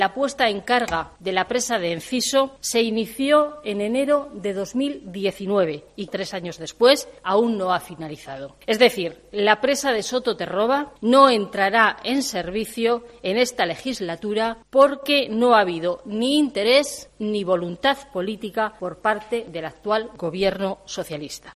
Ana Lourdes González, senadora del PP de La Rioja